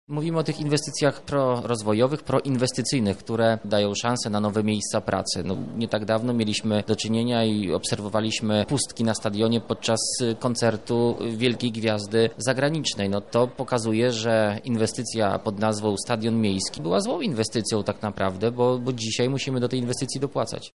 – Z jednej strony dalsze zadłużanie miasta, z drugiej niewykorzystane możliwości poprzez brak realizacji ważnych inwestycji – tak Sylwester Tułajew, przewodniczący radnych Rady Miasta z PiS ocenia rządy Krzysztofa Żuka.